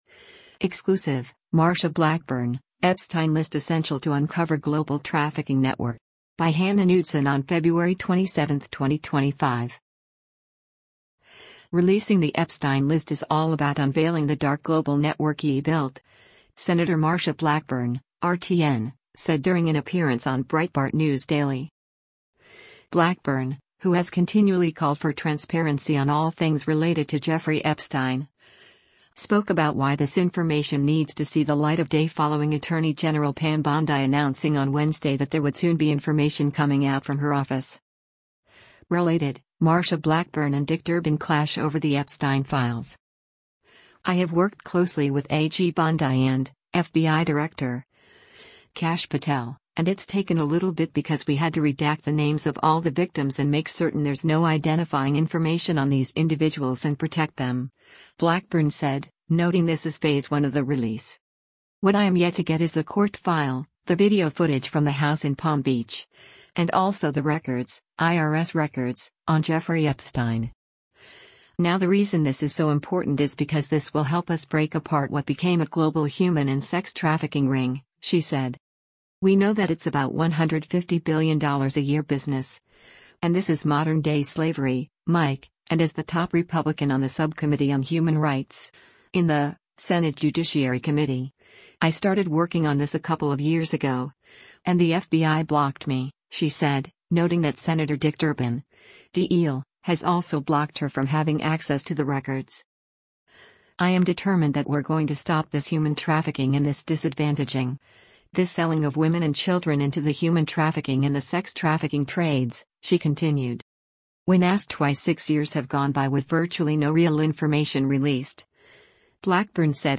Releasing the Epstein list is all about unveiling the dark global network he built, Sen. Marsha Blackburn (R-TN) said during an appearance on Breitbart News Daily.